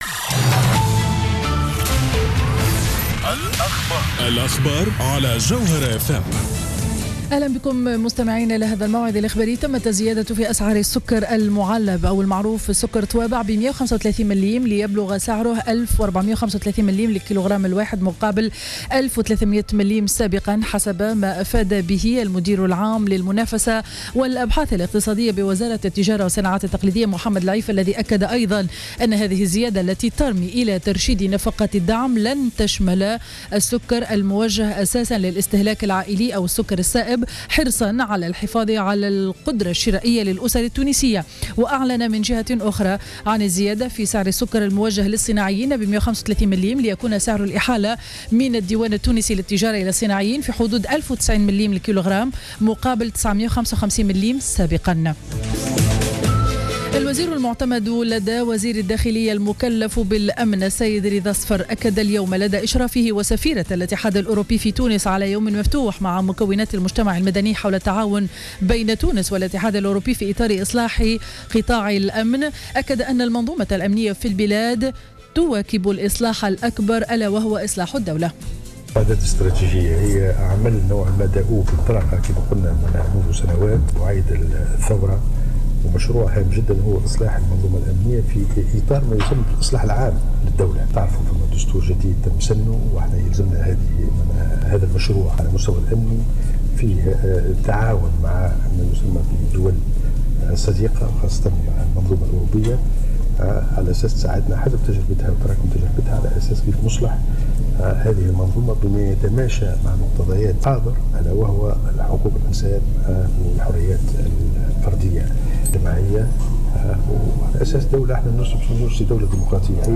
نشرة أخبار منتصف النهار ليوم الثلاثاء 15-07-14